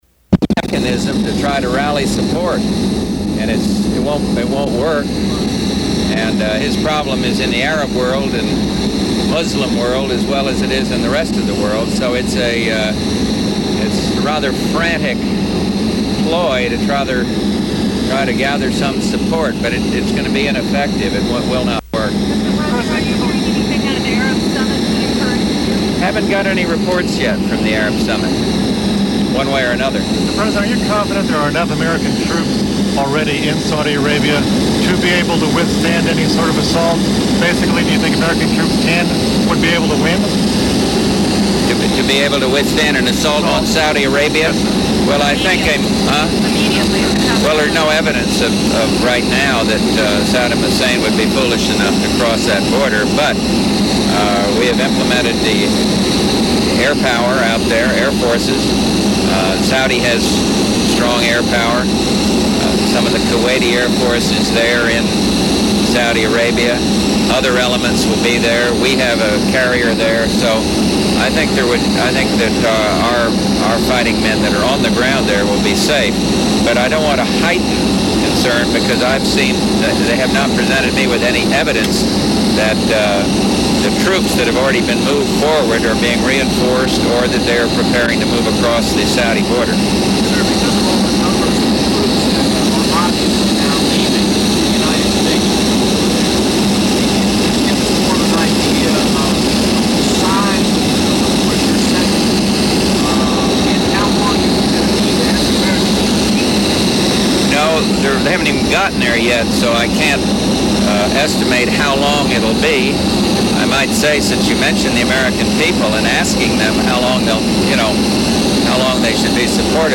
George Bush tells reporters he is encouraged by almost universal worldwide support for the U.S. actions